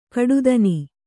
♪ kaḍudani